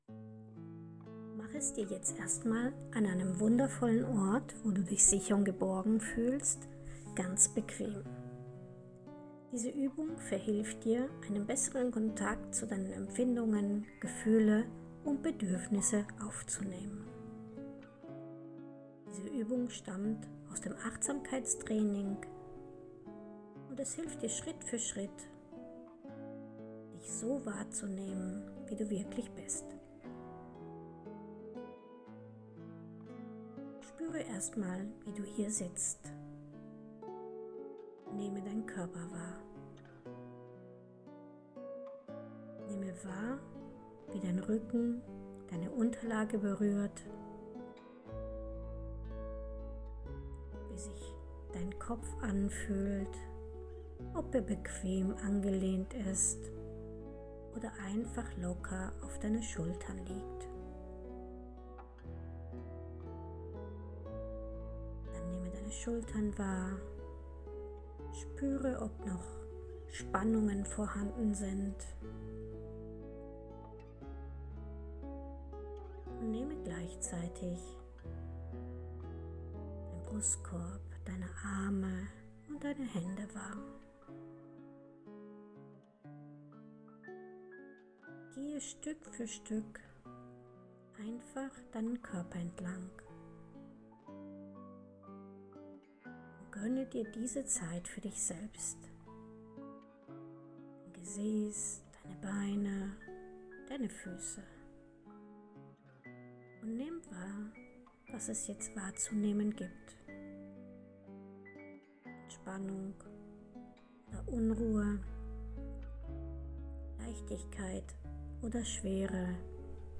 Geführte Meditation: Sich selbst wahrnehmen